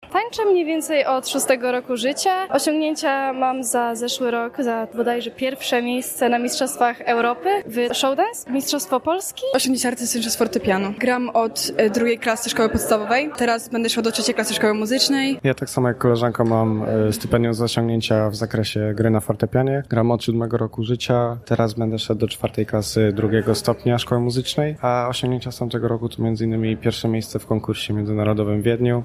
Stypendia przyznano za osiągnięcia w takich dziedzinach, jak taniec, muzyka, sztuki plastyczne i wokalne. Stypendia wręczono wczoraj (18.06) w rzeszowskim Teatrze Maska.